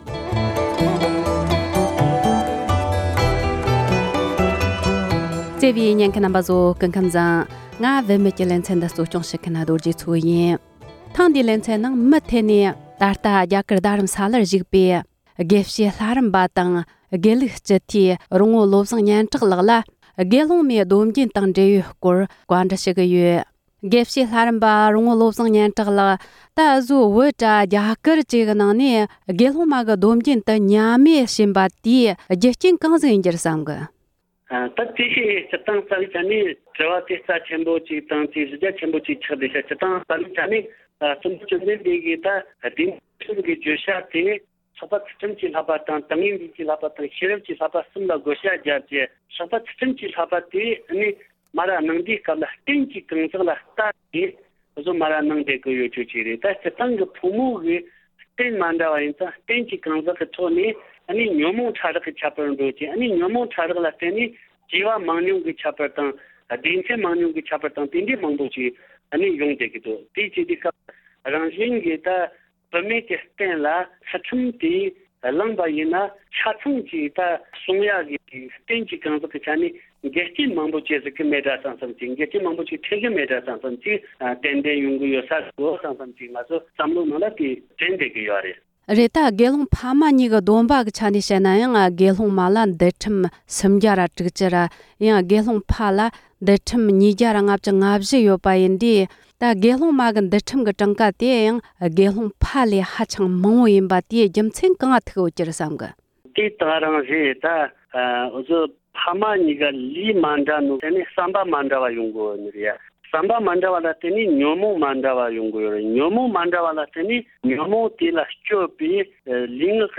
དགེ་སློང་མའི་སྡོམ་རྒྱུན་བསྐྱར་གསོ་ཡོང་ཐབས་ཀྱི་གླེང་མོལ།